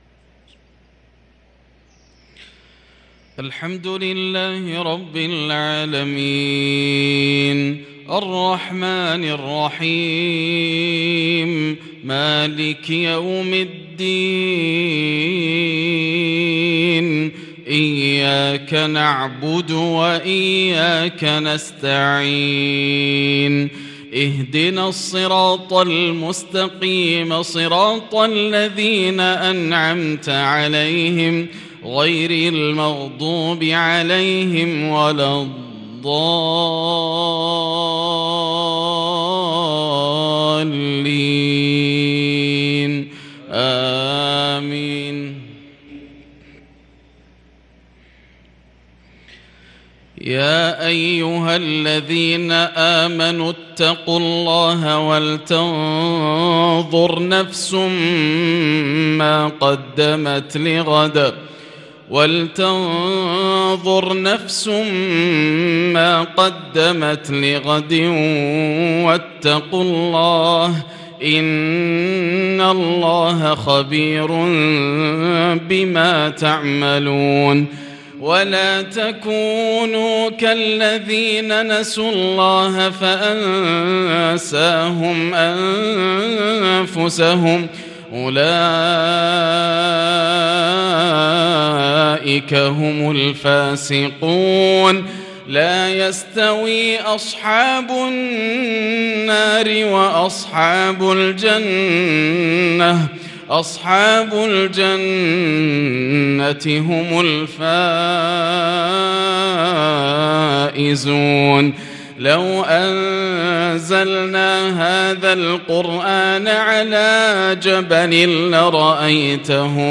صلاة المغرب للقارئ ياسر الدوسري 4 ربيع الأول 1444 هـ